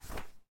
纸质书阅读 " 书籍关闭01
描述：01/36各种书的操作......。翻页，关书，摸索。在我的家庭工作室里，使用一对匹配的Rode NT5的XY配置进行录音。
标签： 本书 关闭 杂志 报纸 纸张 阅读 阅读 打开
声道立体声